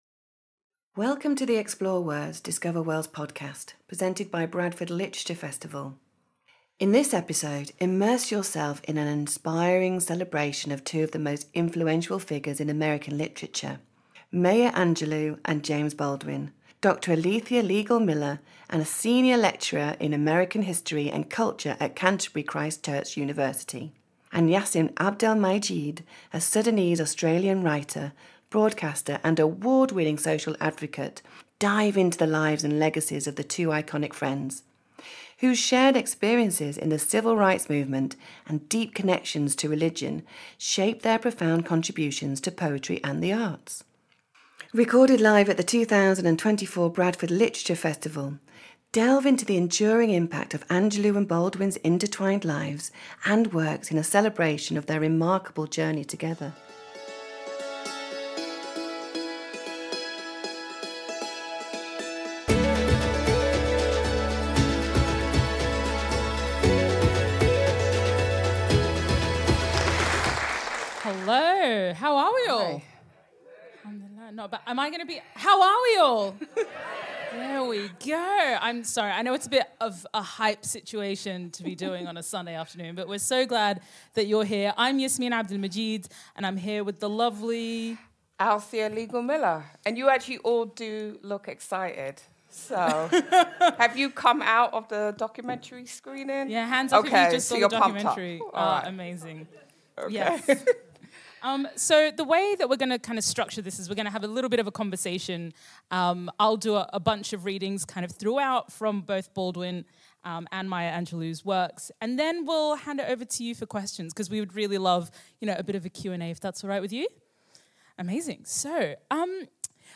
Join us for an enthralling discussion as they delve into the range of artists work, from Beirut to Tunis and beyond. Highlighting the relationship between artists and writers and the influences that inform their work, from family to politics and everything in between.